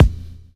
Kick (4).wav